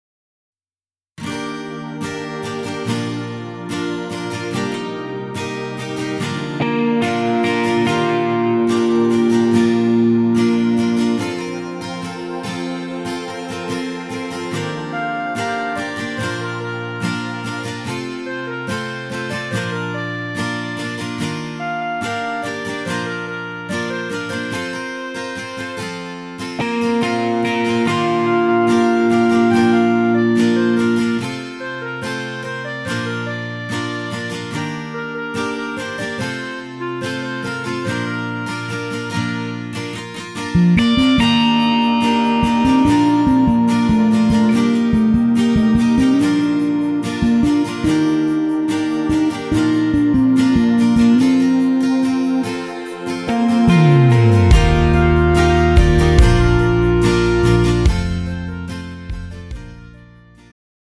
음정 여자-2키
장르 축가 구분 Pro MR